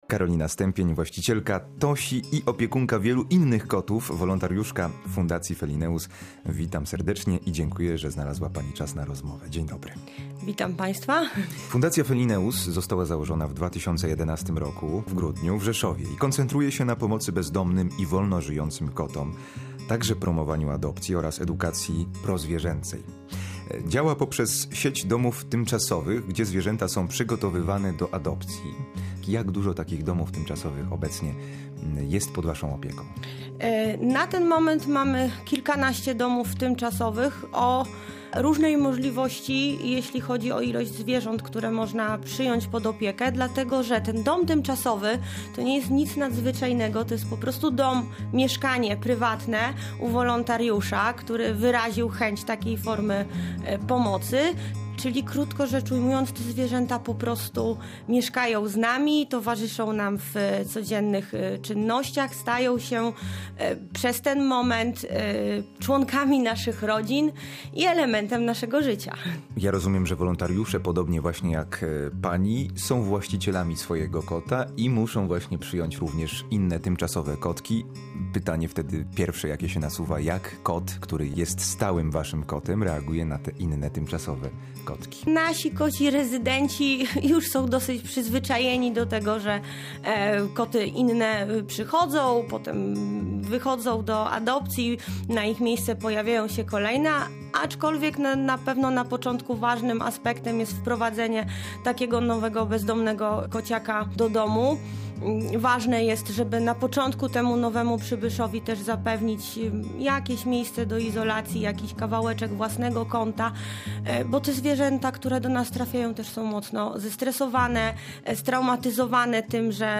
Audycje • Masz pytania dotyczące swojego pupila?